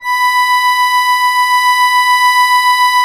MUSETTESW.16.wav